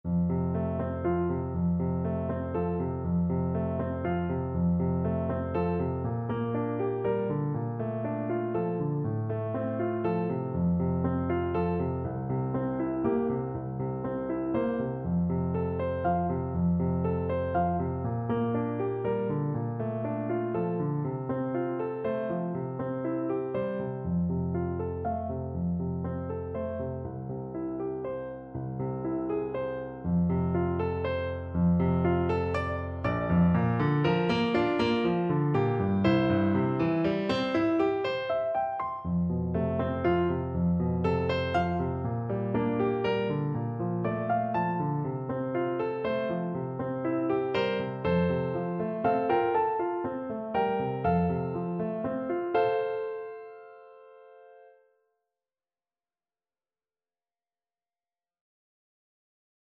6/8 (View more 6/8 Music)
Andantino =c.120 (View more music marked Andantino)
Traditional (View more Traditional French Horn Music)